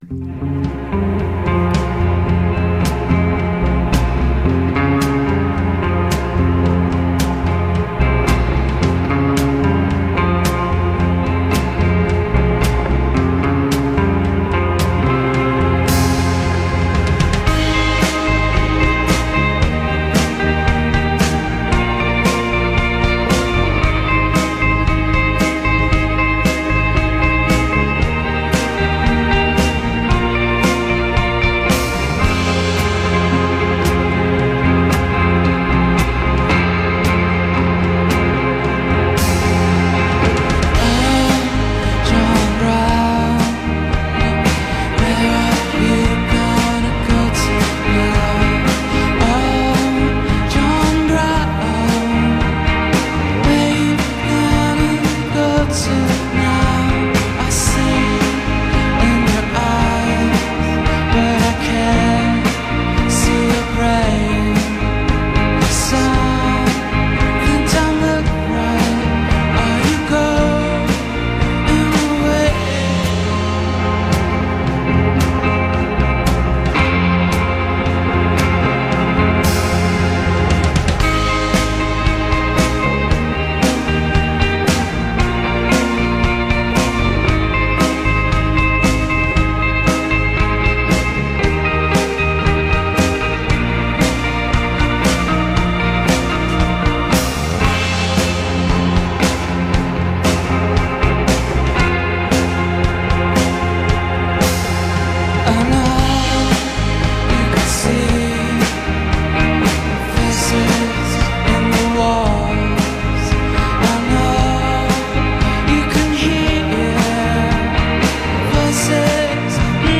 San Francisco Indie/Baroque